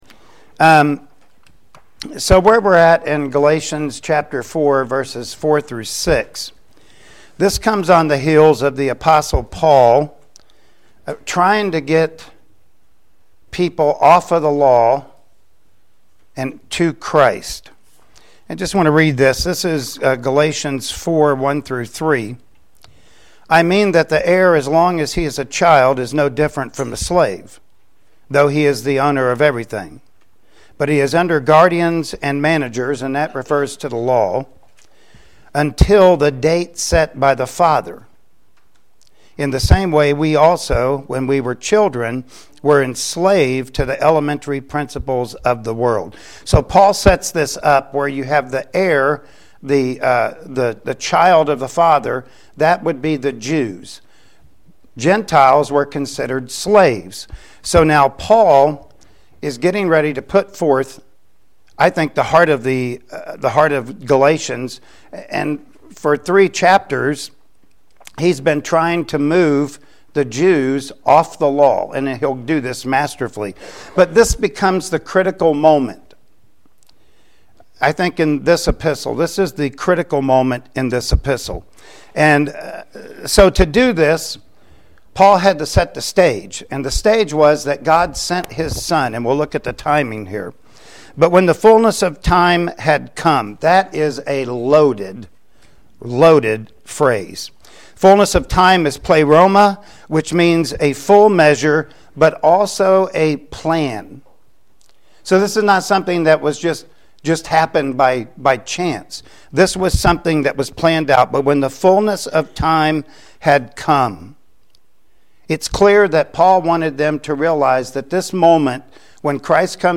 Service Type: Sunday Morning Worship Service Topics: Birth of Jesus